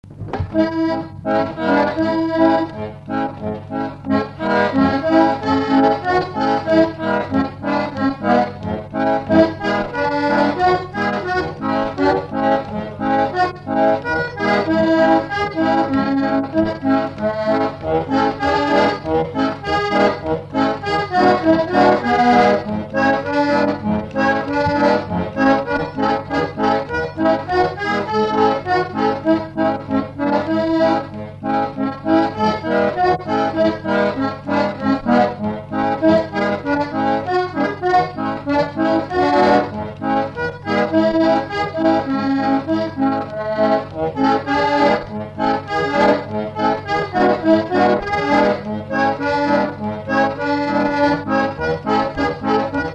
Variété française
Instrumental
Pièce musicale inédite